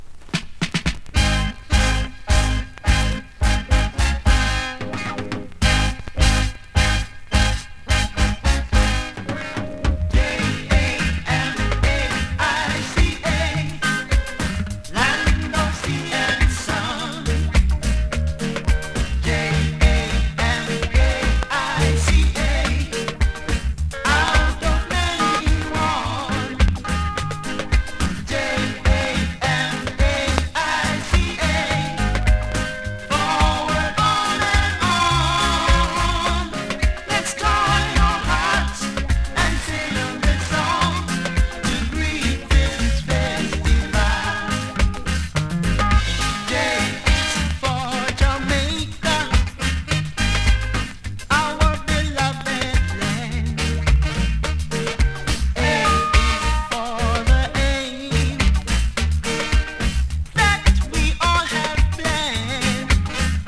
2024!! NEW IN!SKA〜REGGAE
スリキズ、ノイズかなり少なめの